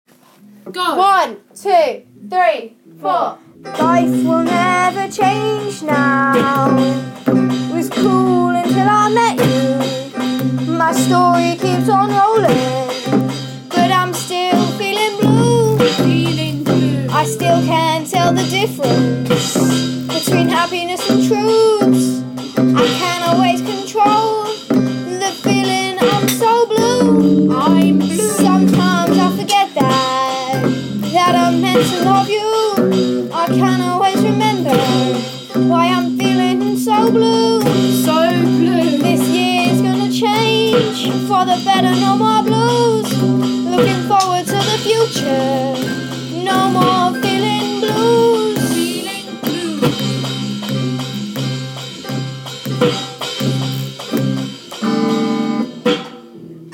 live blues song